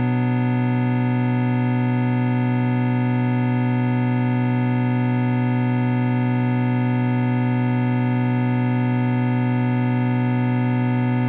bm-chord.ogg